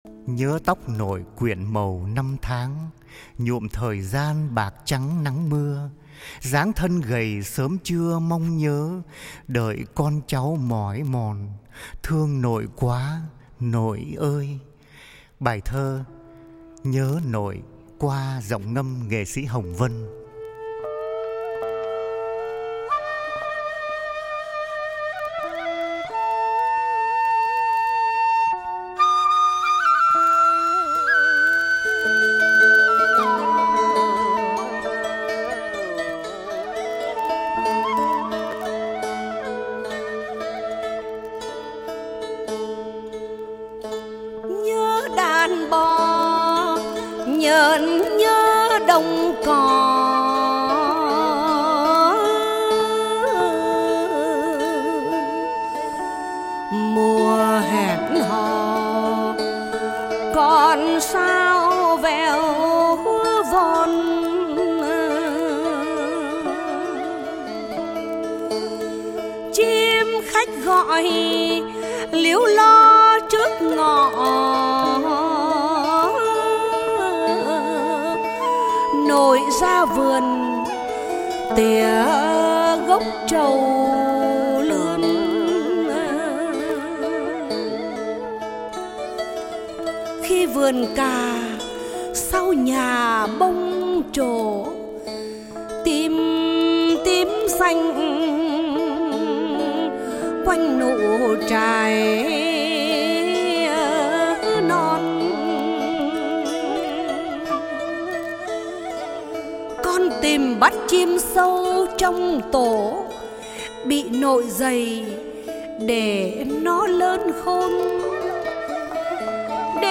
Ngâm Thơ | Sáng Tạo